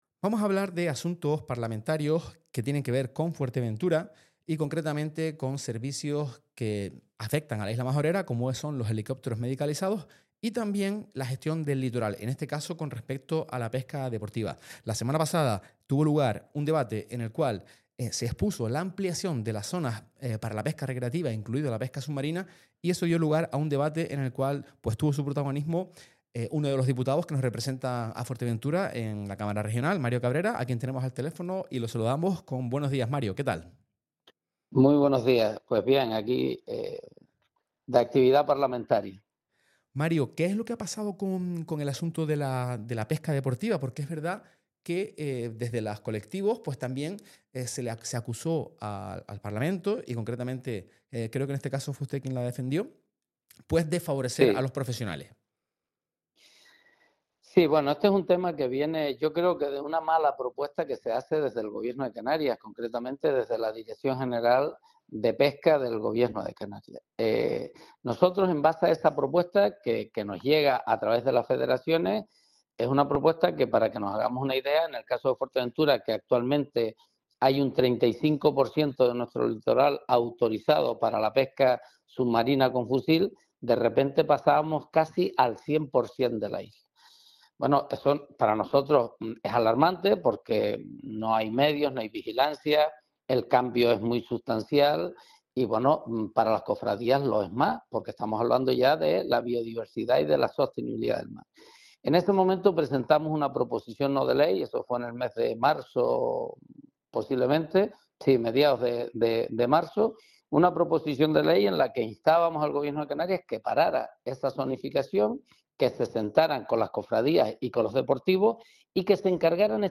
Entrevistas y declaraciones